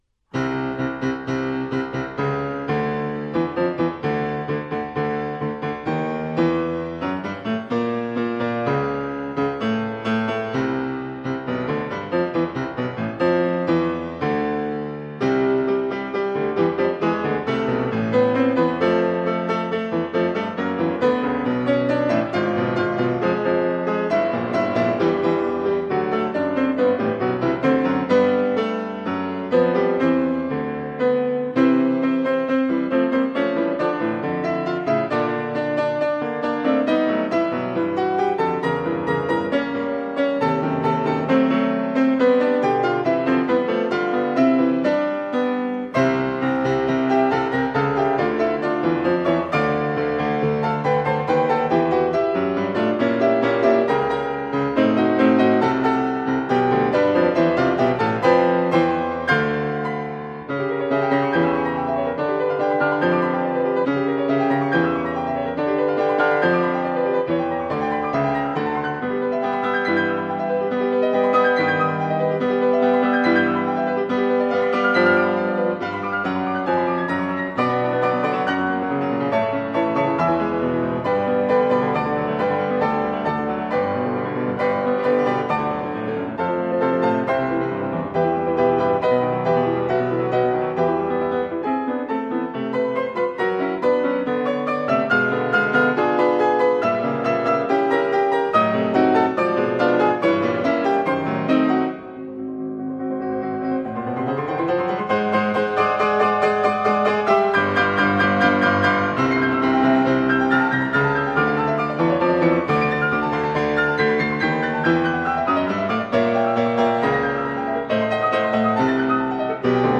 钢琴
录音格式： ADD
样，演奏风格清晰明快，具有丰富的色彩变化和深厚的音乐涵养。